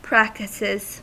Ääntäminen
Ääntäminen US Haettu sana löytyi näillä lähdekielillä: englanti Practices on sanan practice monikko.